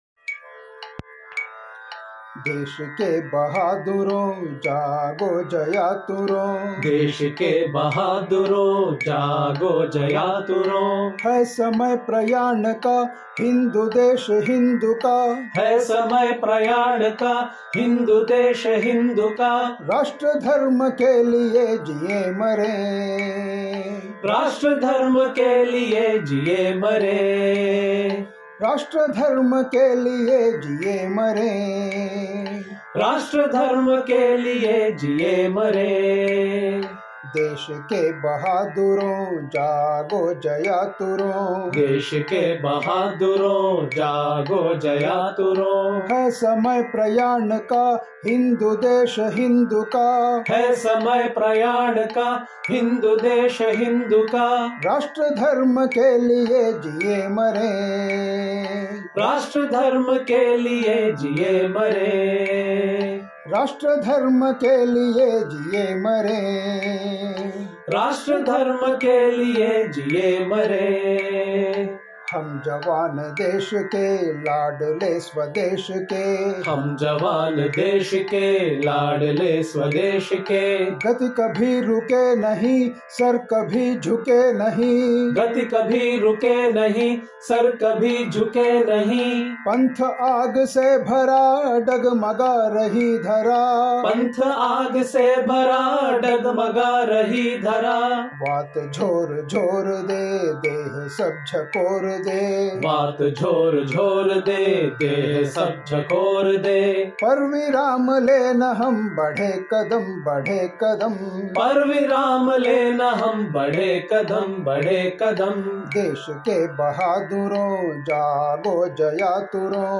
Patriotic Songs Collections